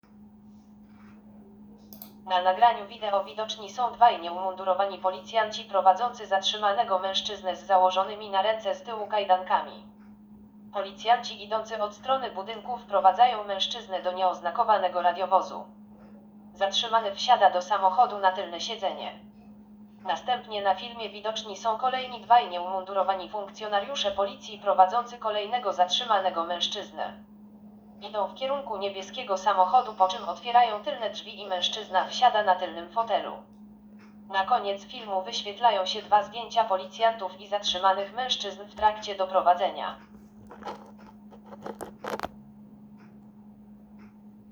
Nagranie audio Audiodeskrypcja_filmu_Policjanci_z_Ryk_doprowadzaja_zatrzymanych_mezczyzn.m4a